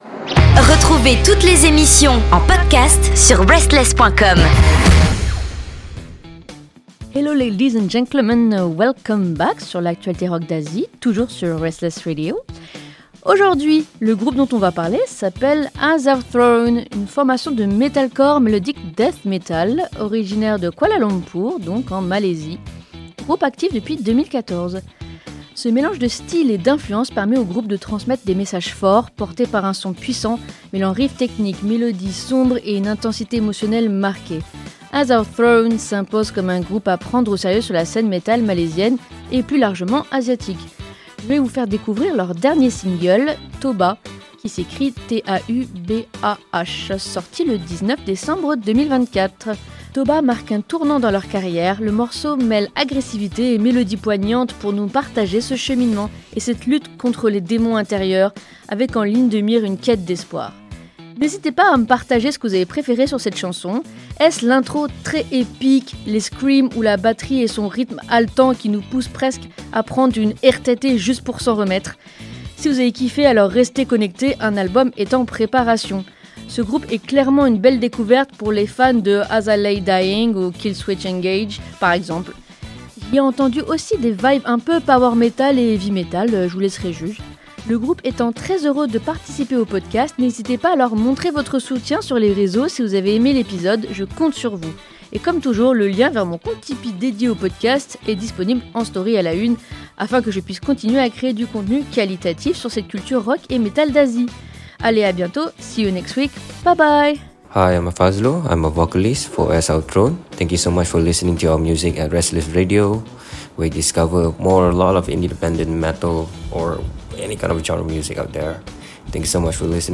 Aujourd’hui, on met le cap sur la scène metal de Malaisie avec AS OUR THRONE, un groupe de death metal mélodique qui frappe fort. Puissance, technique et émotions à fleur de riffs : préparez-vous à une claque sonore venue de Kuala Lumpur.